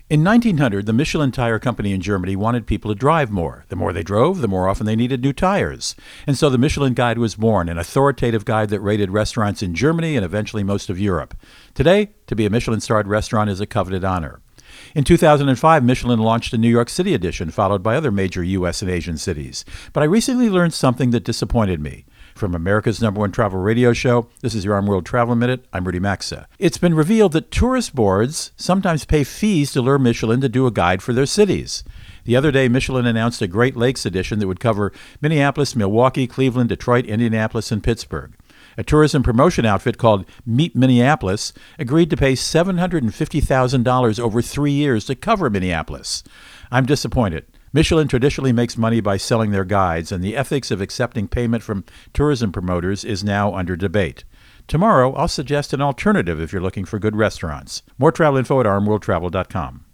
America's #1 Travel Radio Show
Co-Host Rudy Maxa | Michelin Guide Behind The Scenes Story